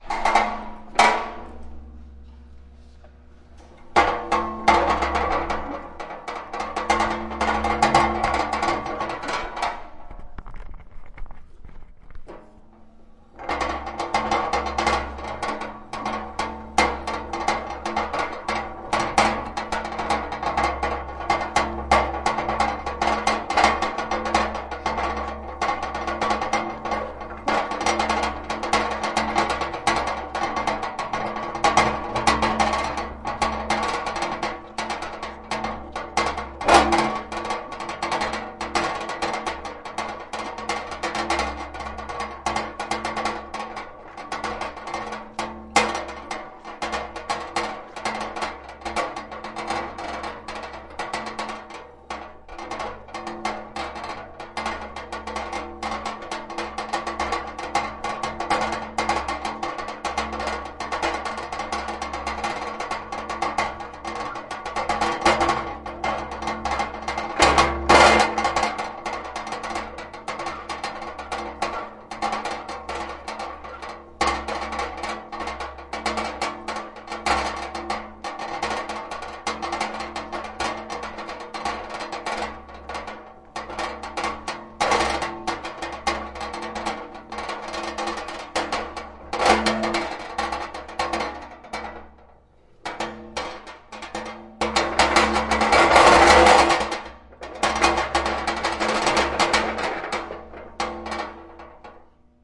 随机" 金属锻模车推过仓库的颠簸 嘎嘎作响的立体声透视 不平衡（左重右轻）
描述：金属小车推车通过仓库颠簸拨浪鼓立体透视不平衡（左重）.flac